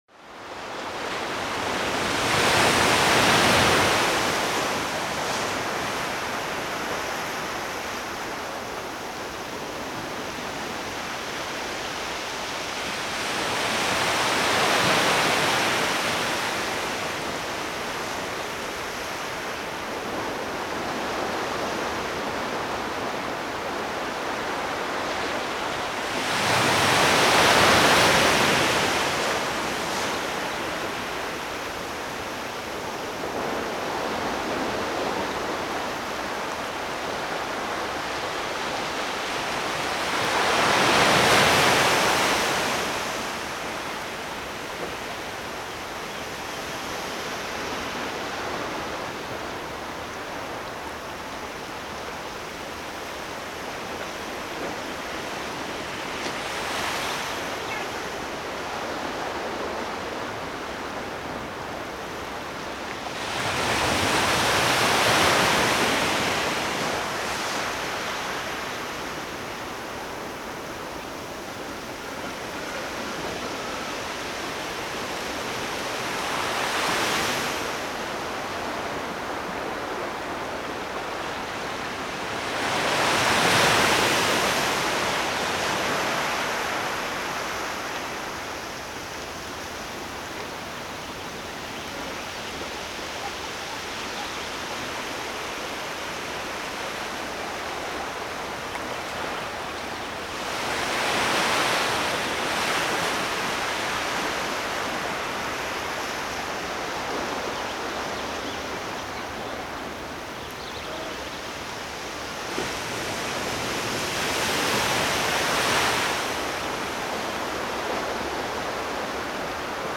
Звук Моря